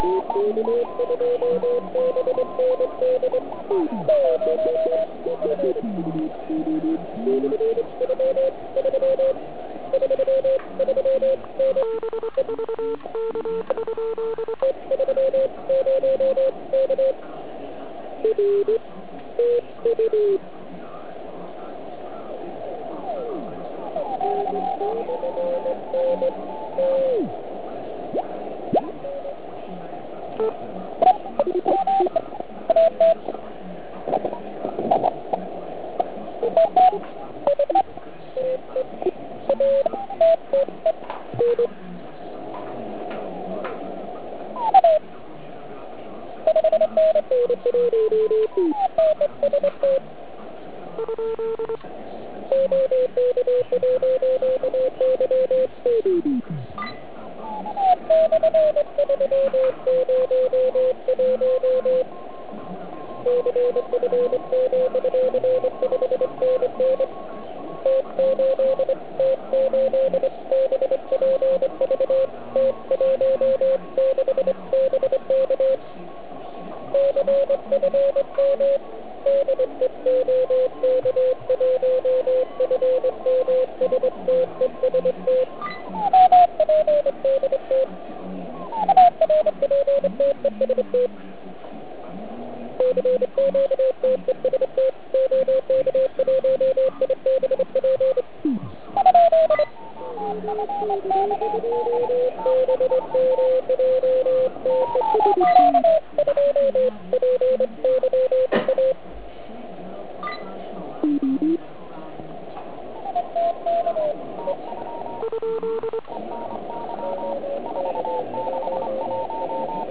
Band scan při OK QRP závodě (*.wav 1,4 MB)